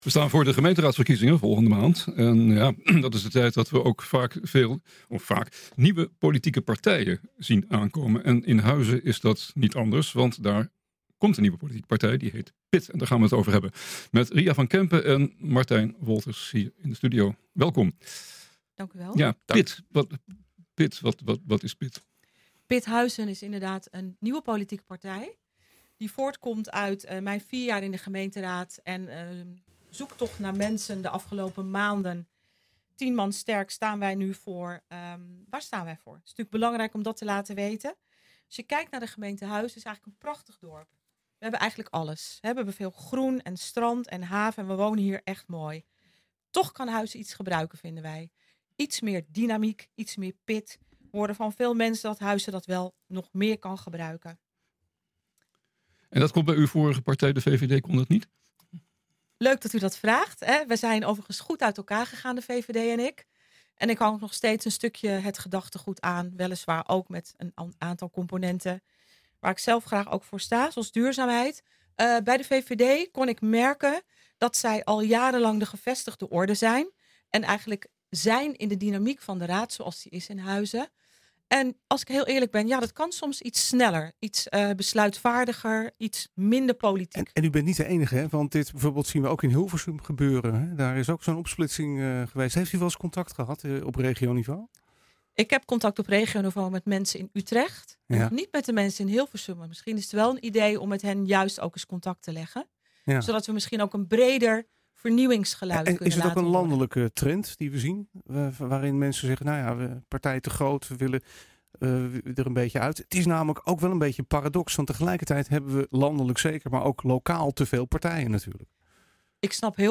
NH Gooi is de streekomroep voor Hilversum, Huizen, Blaricum, Eemnes en Laren.